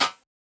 Keyflo Prince Style Perc 2.wav